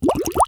Wet / Bubbles